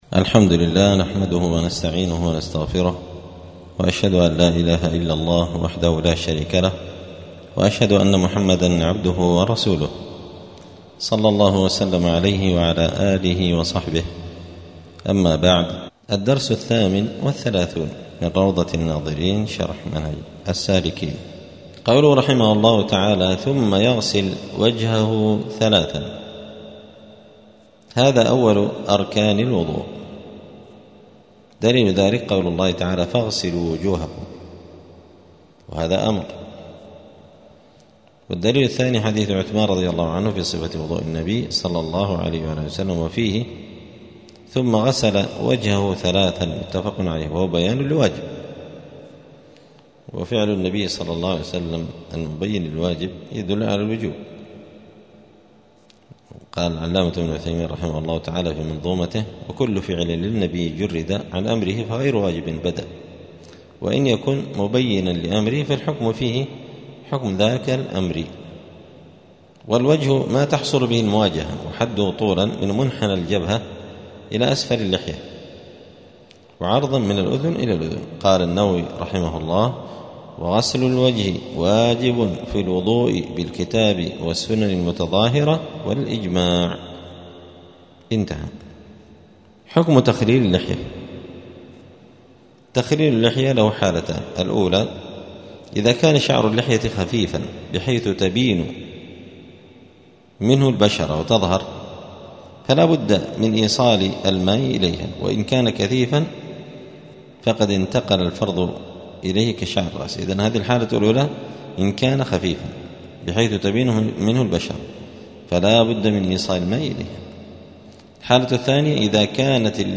*الدرس الثامن والثلاثون (38) {كتاب الطهارة باب صفة الوضوء غسل الوجه واليدين إلى المرافق}*
دار الحديث السلفية بمسجد الفرقان قشن المهرة اليمن